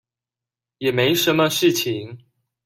Голоса - Тайваньский 119